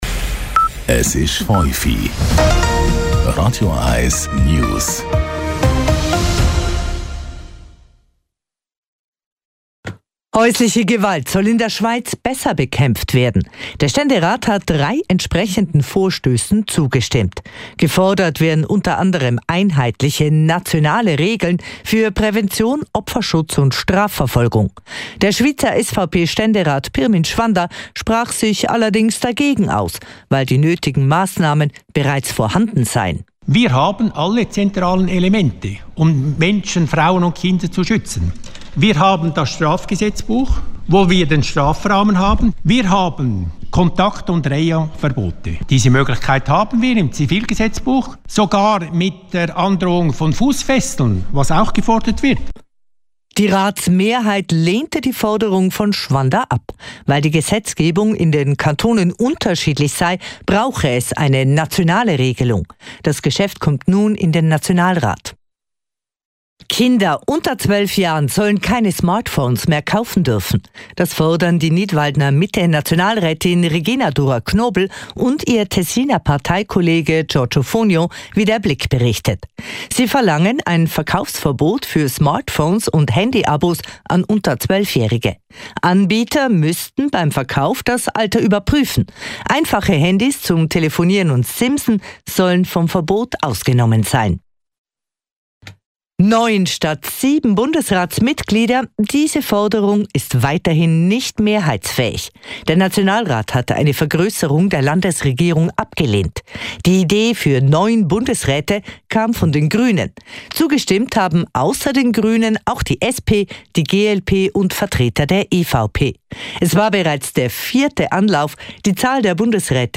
Die letzten News von Radio 1